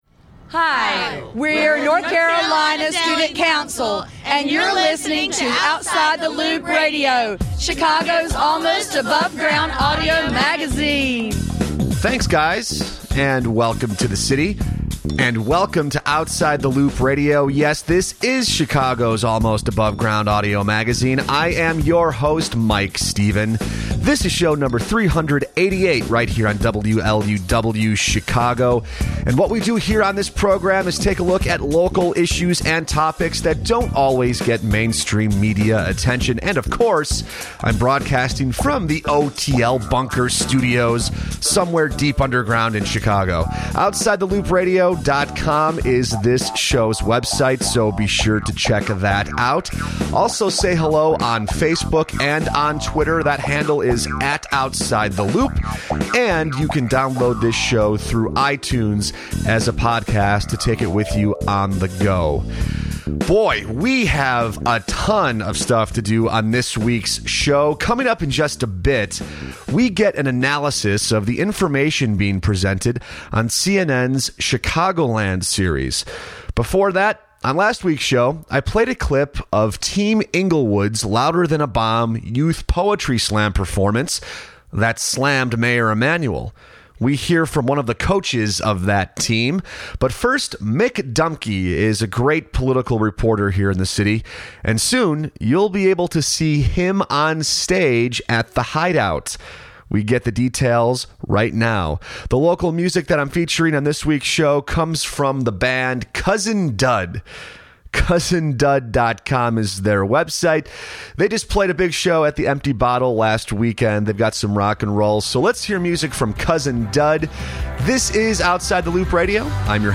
Outside the Loop Radio Interview
The interview is from 12:00-22:00 https